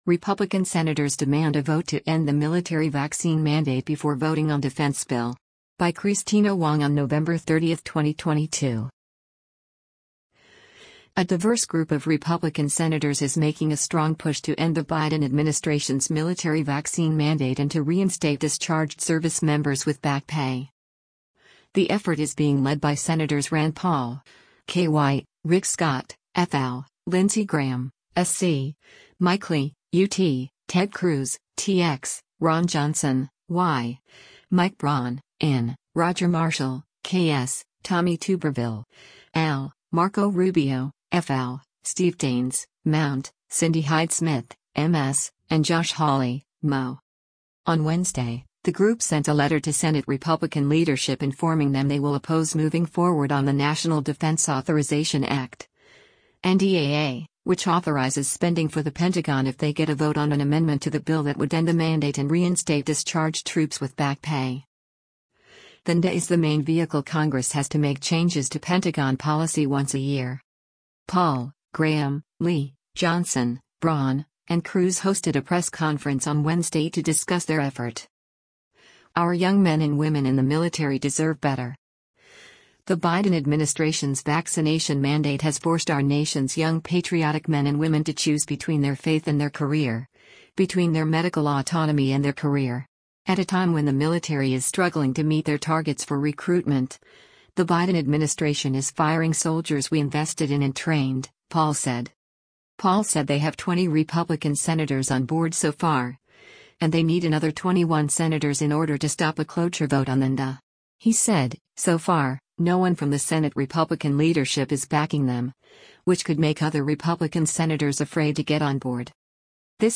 Paul, Graham, Lee, Johnson, Braun, and Cruz hosted a press conference on Wednesday to discuss their effort.